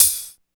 28 HAT 3.wav